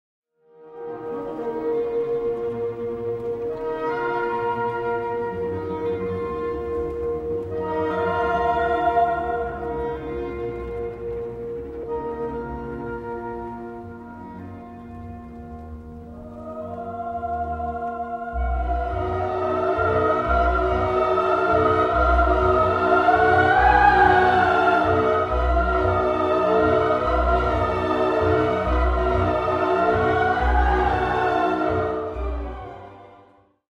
At the beginning of the movement low strings and harp provide a muted background centered on an F-sharp-major chord, like the piece’s predecessor Printemps.
The initial motive presented by the horns (m. 1), is immediately echoed in measure 2 by the female chorus.
Belgian Radio & Television Orchestra